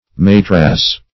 matrass \ma*trass"\, n. [F. matras; perh. so called from its